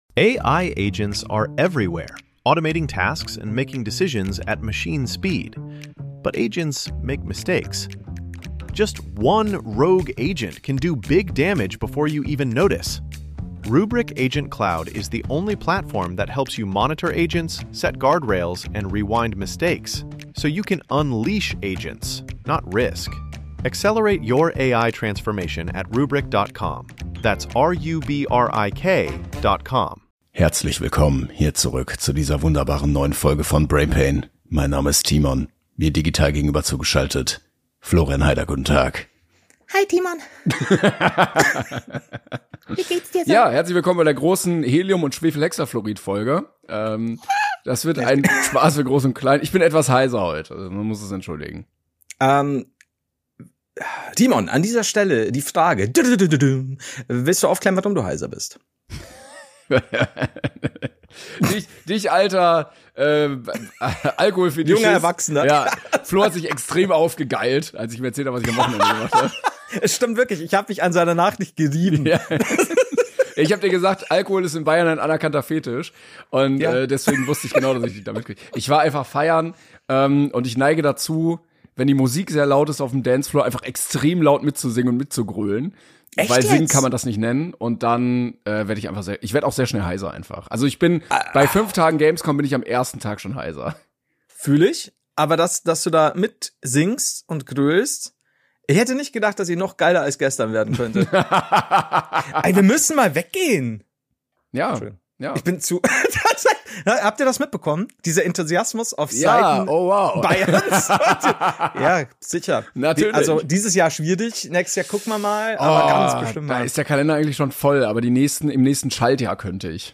mit sehr tiefer Bass-Stimme